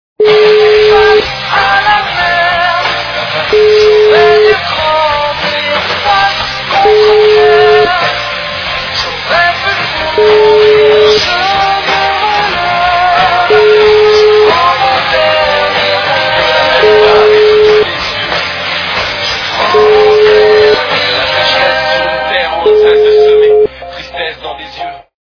западная эстрада
качество понижено и присутствуют гудки.